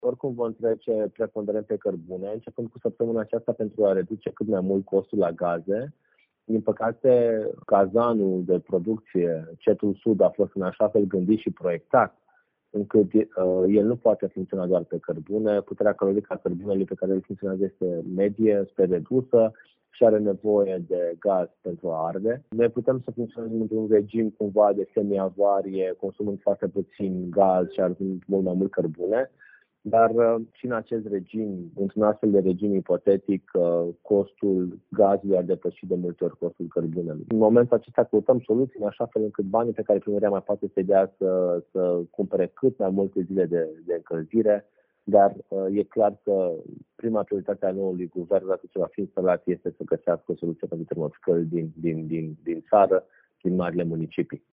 Oprirea gazului pentru Colterm de către Eon nu va duce automat la oprirea căldurii, susține viceprimarul Ruben Lațcău.